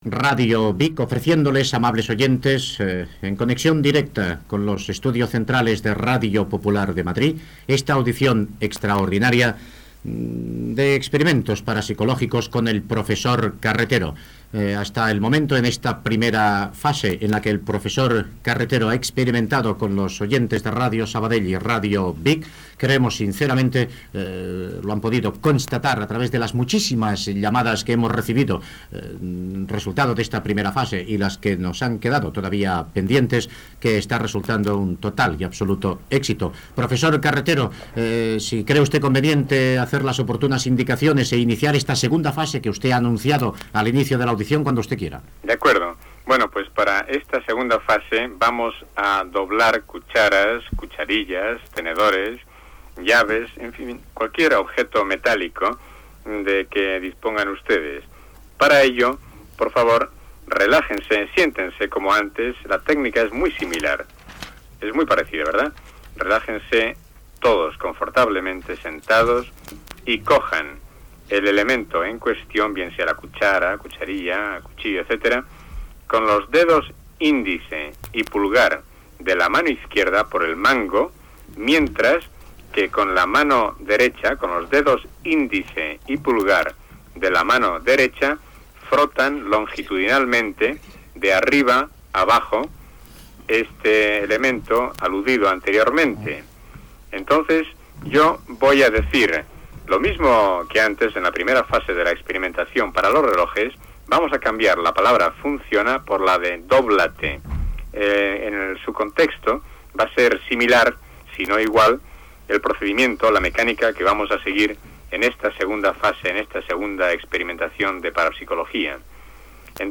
47fb5bd876294f0b9eeaa39a1086dca2f3d59c6e.mp3 Títol Ràdio Vic Emissora Ràdio Vic Titularitat Privada local Nom programa Fenómenos parasicólogos Descripció Espai fet amb connexió amb Ràdio Sabadell.
Telèfons de Ràdio Vic i Ràdio Sabadell per donar testimonis, trucades telefòniques de l'audiència, pregunta sobre els extraterrestres